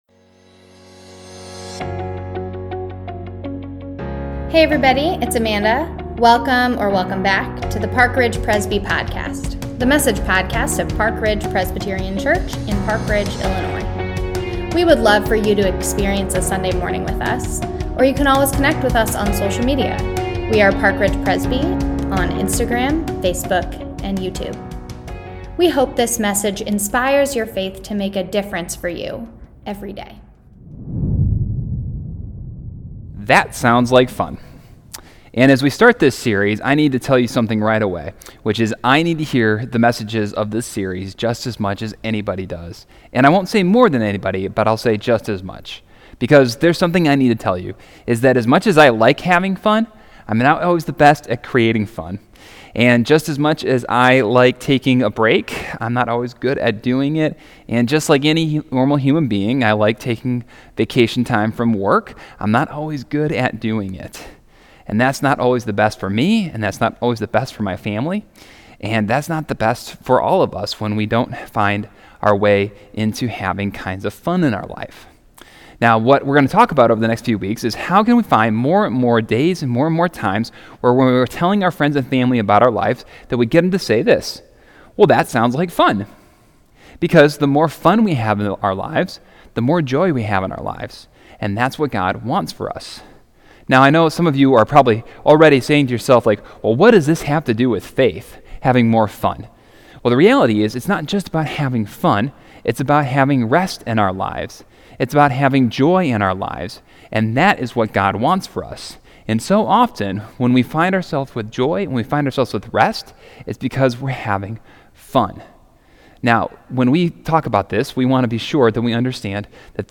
July-11-Sermon.mp3